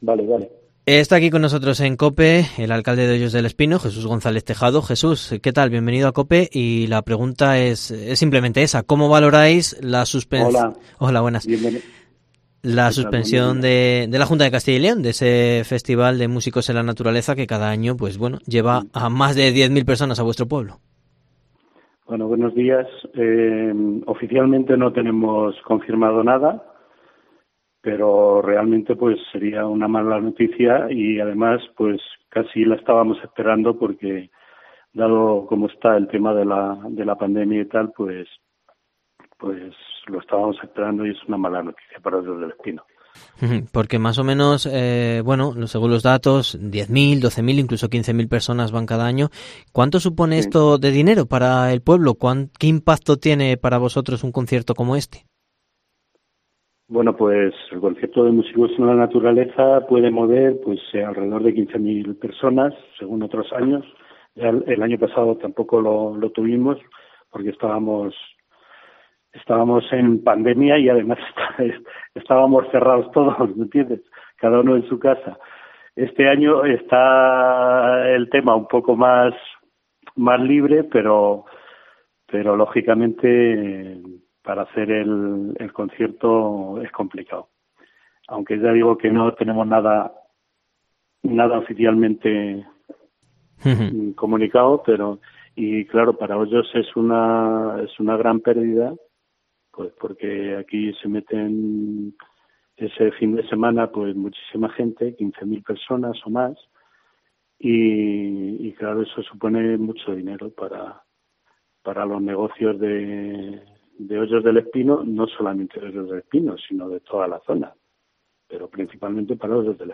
El alcalde del pueblo, Jesús González Tejado se ha enterado de la noticia gracias a COPE y lamenta la perdida económica que supondrá para toda la zona de Gredos. (Escuchar entrevista).